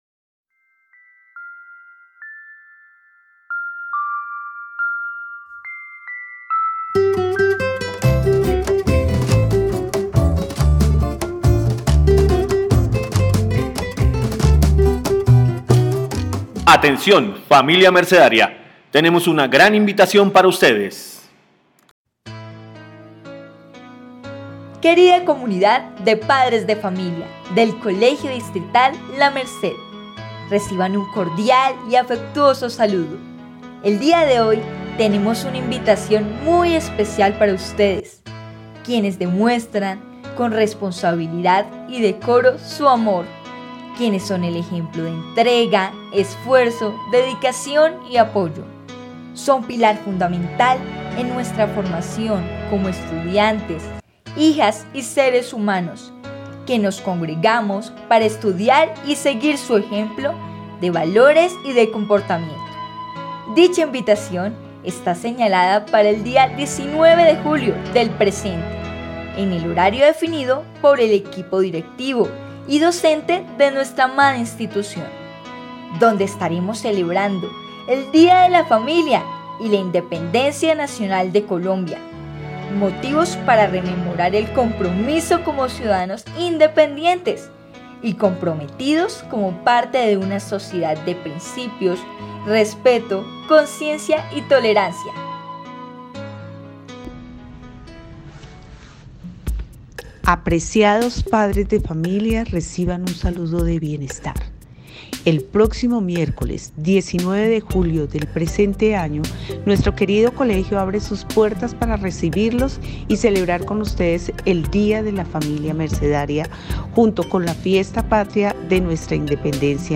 Anuncio emisora